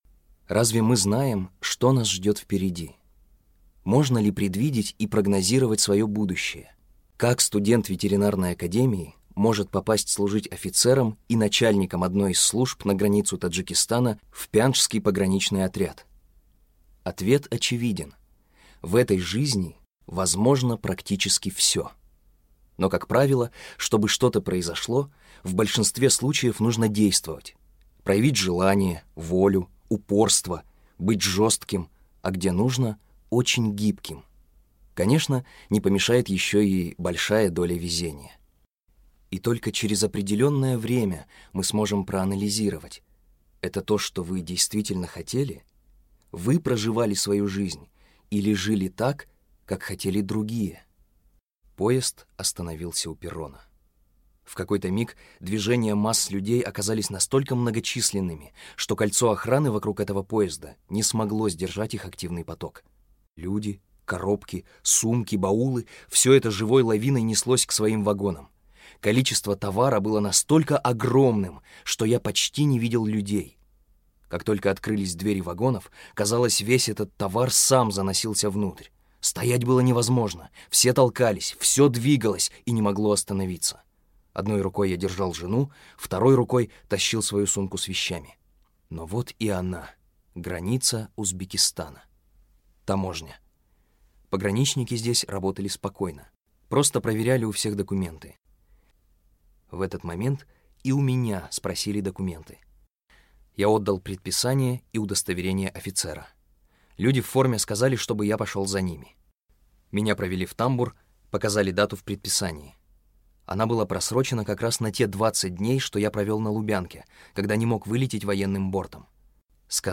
Аудиокнига Жизнь и граница | Библиотека аудиокниг